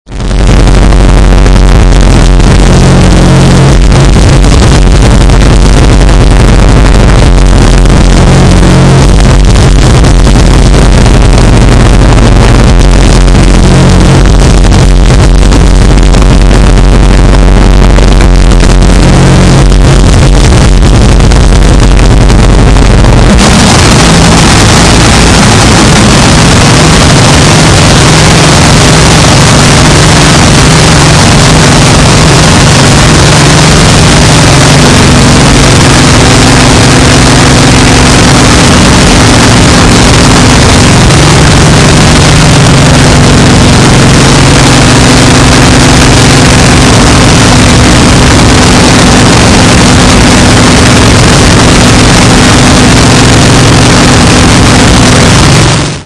harsh noise storm